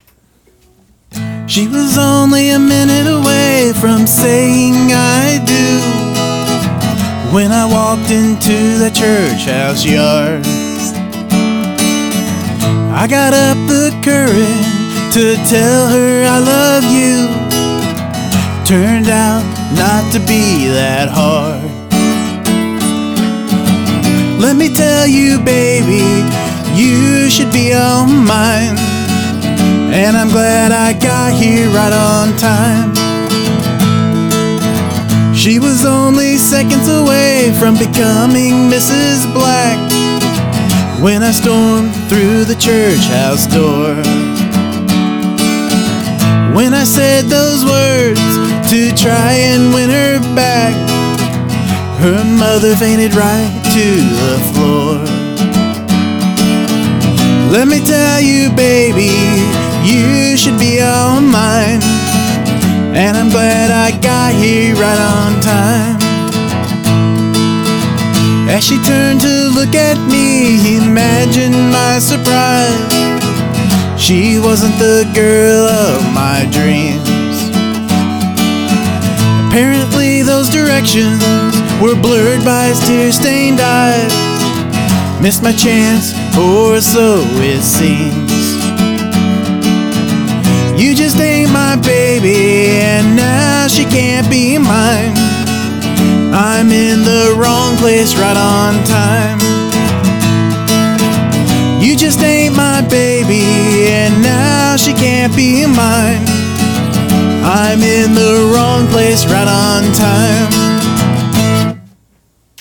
This is where a title is posted and then an hour is given to write and record a song.
even if it does sound pretty Green Dayish.